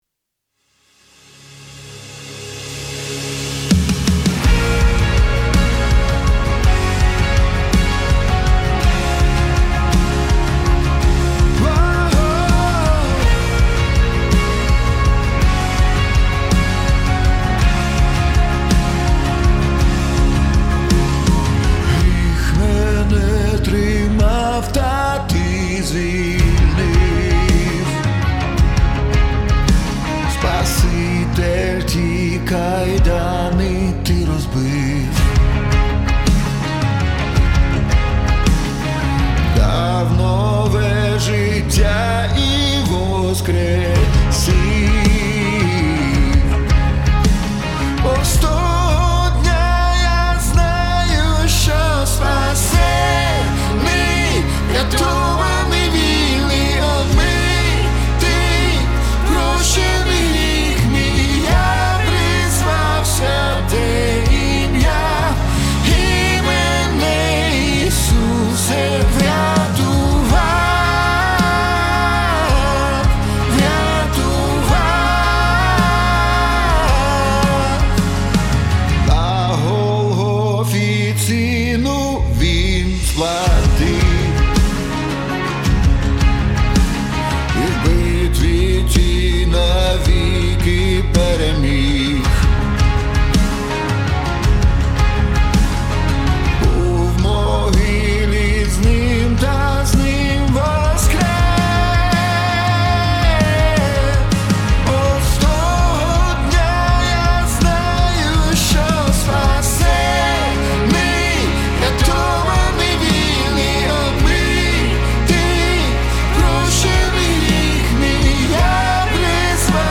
99 просмотров 236 прослушиваний 8 скачиваний BPM: 164